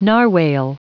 Prononciation du mot narwhale en anglais (fichier audio)
Prononciation du mot : narwhale